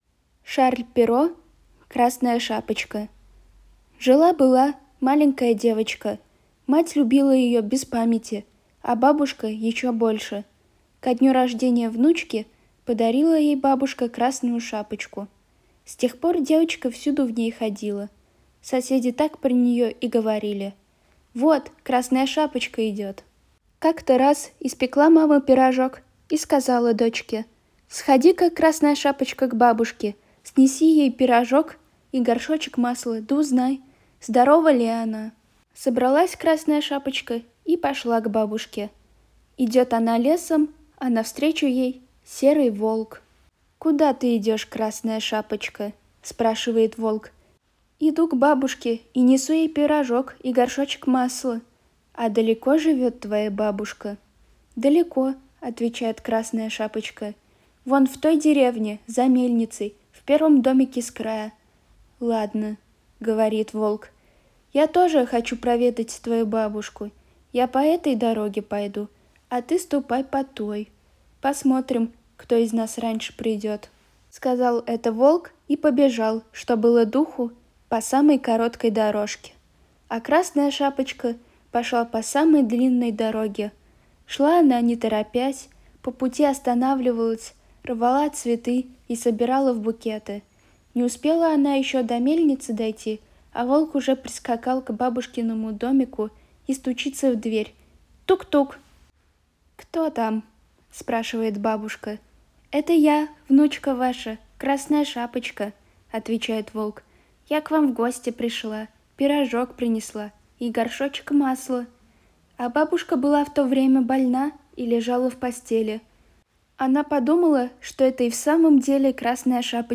Слушайте аудиоверсию в нашем проекте «Волонтёры читают»!